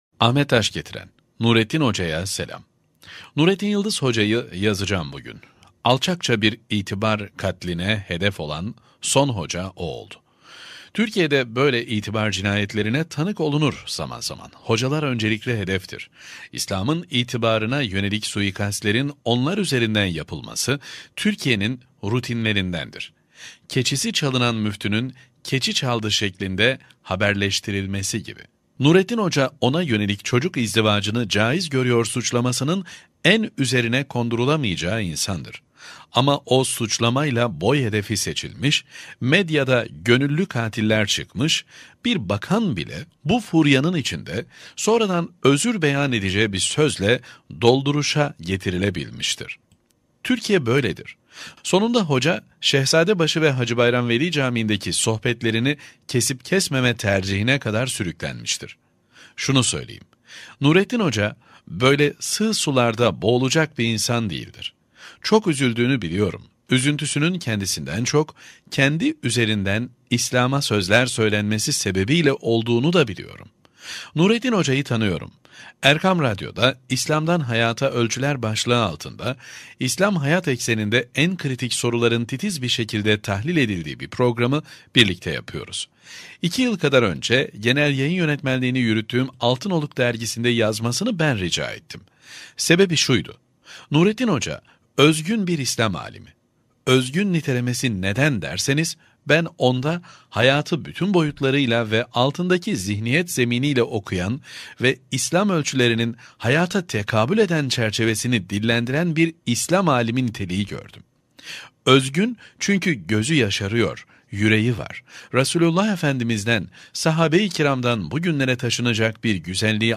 2. Sesli Makale